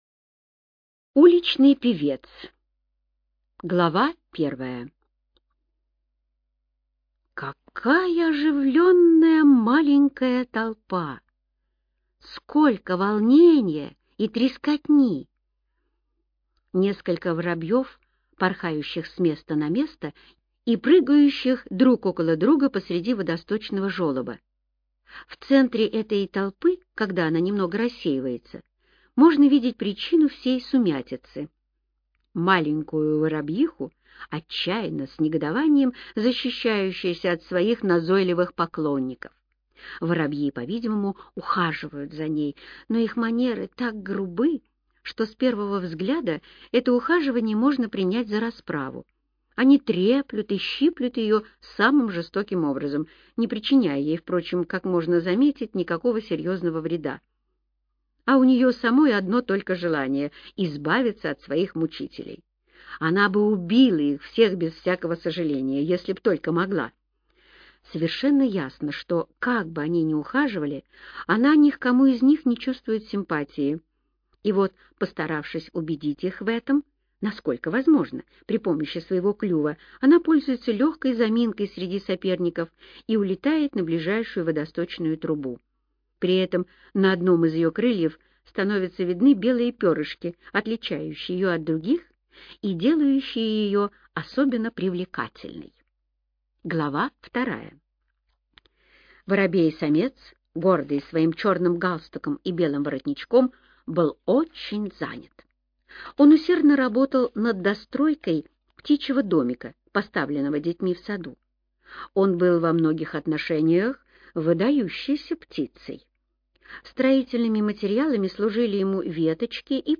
Уличный певец - аудио рассказ Эрнеста Сетона-Томпсона - слушать онлайн